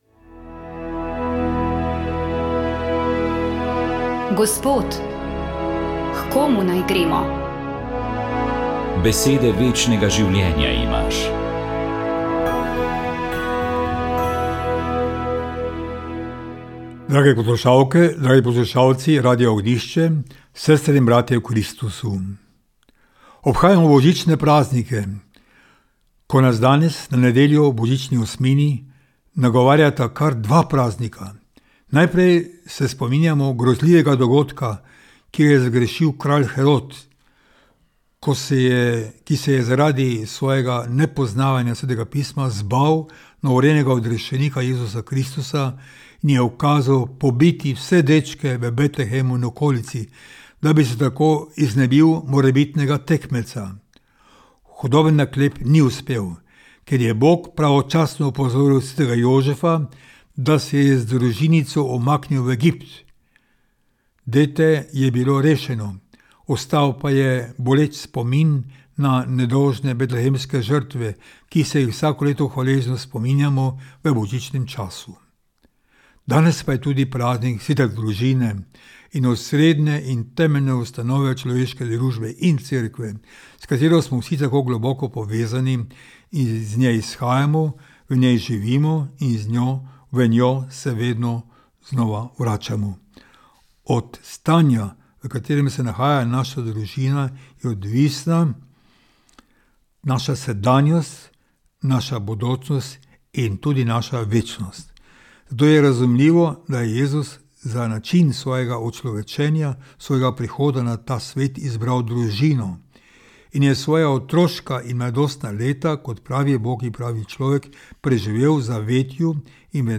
Duhovni nagovor
Duhovni nagovor je pripravil upokojeni ljubljanski nadškof msgr. dr. Anton Stres.